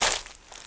STEPS Leaves, Walk 12.wav